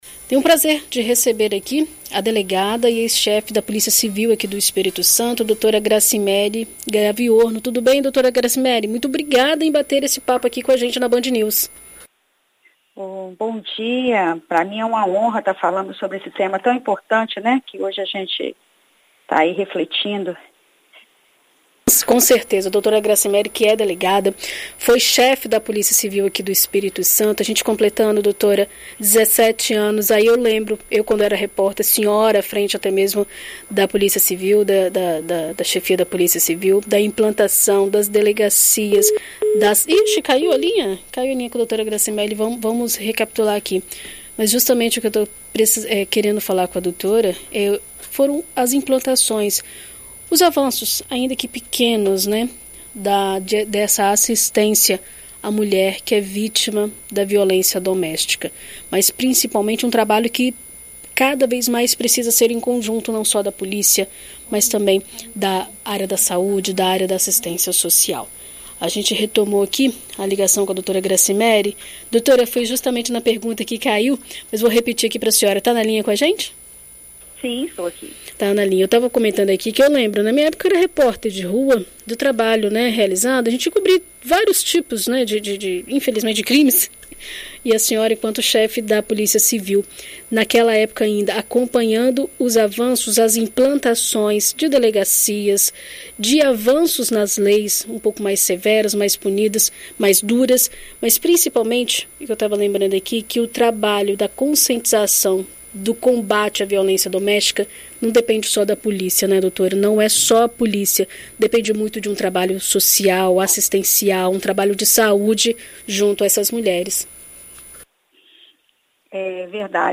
Em entrevista à BandNews FM Espírito Santo nesta segunda-feira (07), a delegada e ex-chefe da Polícia Civil do Espírito Santo, Gracimeri Gaviorno, fala sobre os avanços da lei Maria da Penha e como tem sido realizado o trabalho de redução da violência doméstica contra as mulheres no estado.